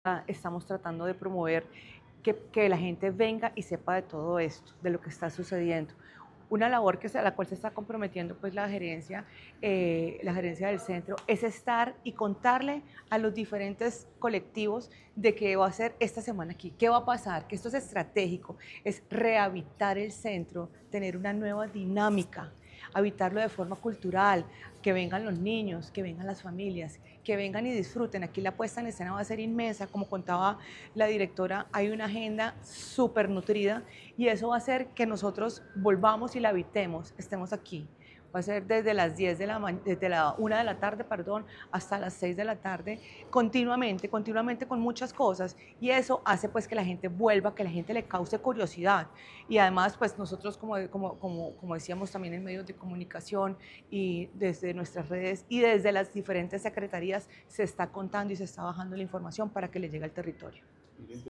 Declaraciones-gerente-del-Centro-y-Territorios-Estrategicos-Juliana-Coral.mp3